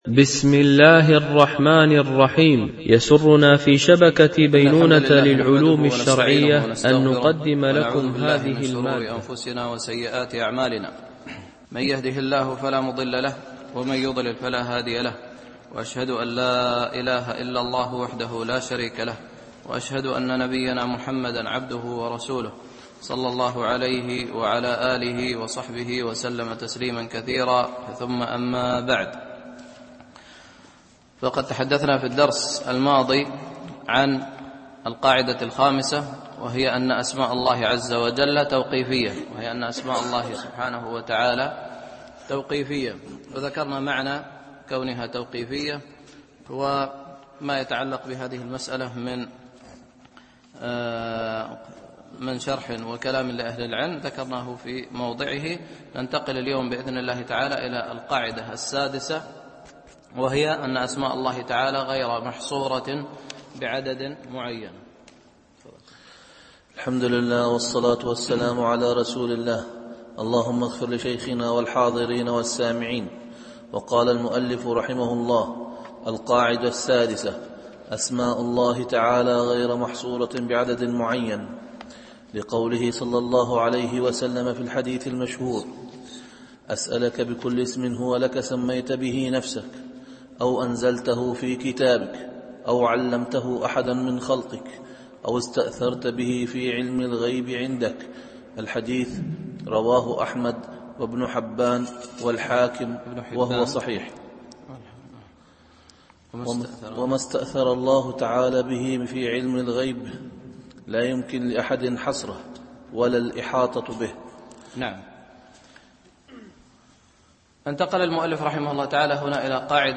شرح القواعد المثلى في صفات الله وأسمائه الحسنى ـ الدرس 6 (قواعد في أسماء الله - القاعدة 6)